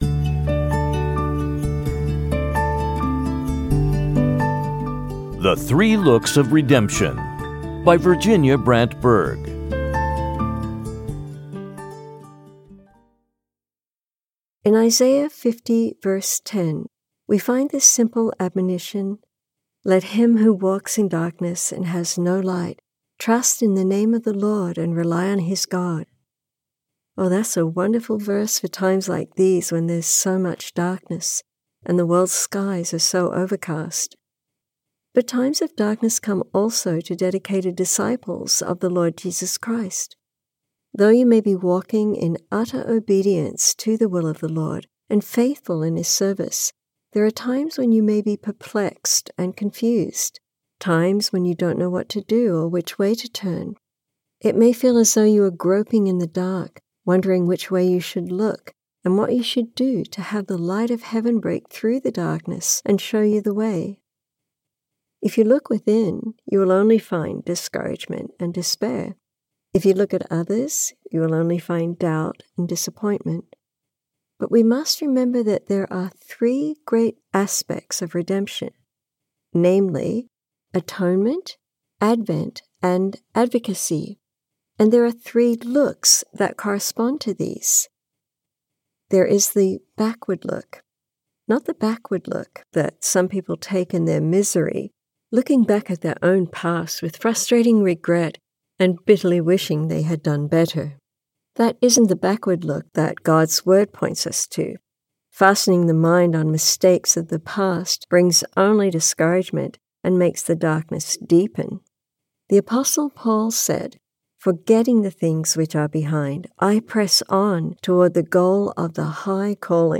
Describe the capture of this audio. From a transcript of a Meditation Moments broadcast, adapted.